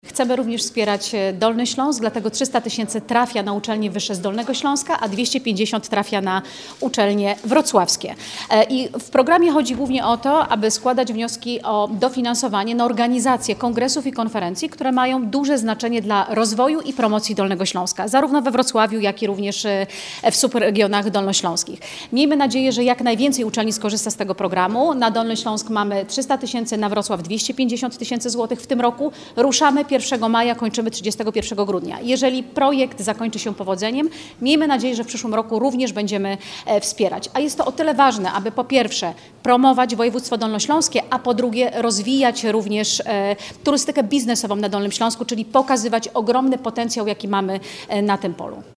W sumie do rozdysponowania jest 550 tys. zł – 300 tys. zł dla uczelni z regionu poza Wrocławiem oraz 250 tys. zł dla uczelni działających w stolicy regionu – wyjaśnia radna Magdalena Piasecka, Radna Sejmiku Województwa Dolnośląskiego.